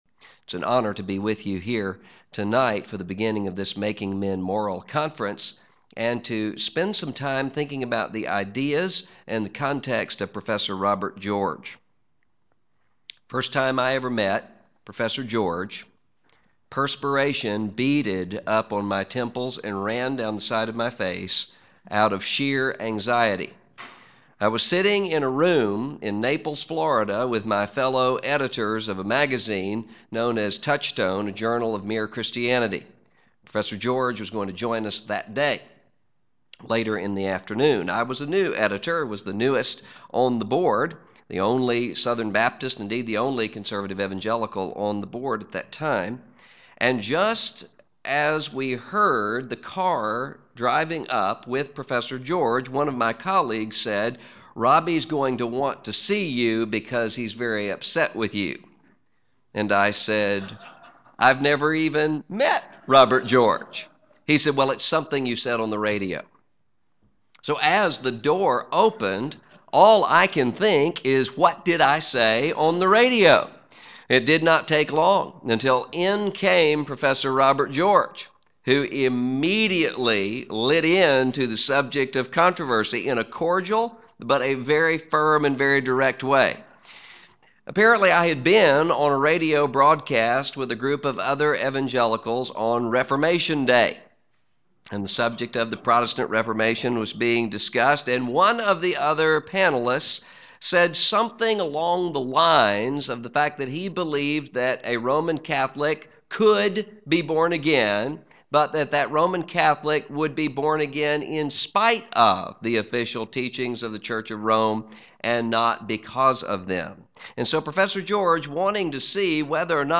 Prof. of Christian Theology, The Southern Baptist Theological Seminary Address: Wed. Evening Address - Russell Moore Recording Date: Feb 25, 2009, 7:30 p.m. Length: 57:14 Format(s): WindowsMedia Audio ; RealAudio ; MP3 ;